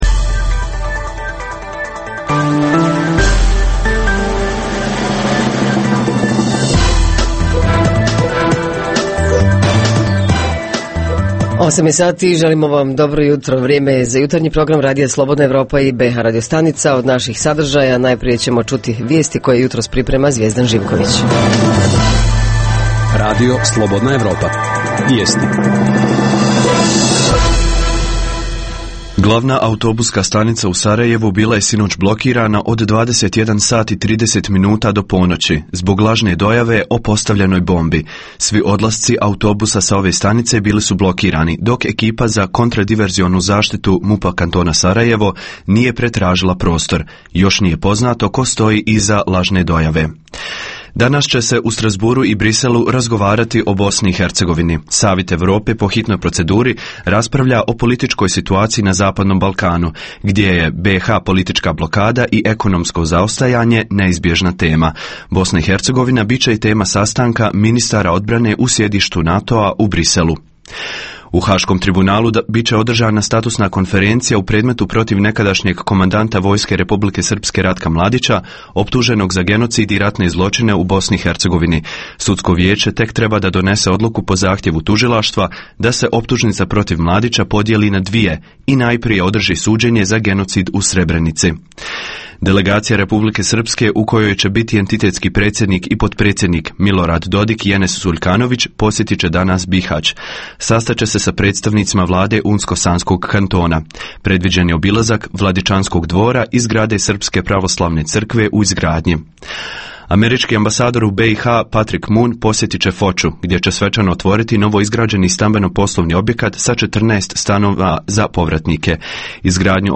Zajednička tema: Iskorištenost odnosno neiskorištenost prirodnih bogatstava u našoj zemlji Reporteri iz cijele BiH javljaju o najaktuelnijim događajima u njihovim sredinama.
Redovni sadržaji jutarnjeg programa za BiH su i vijesti i muzika.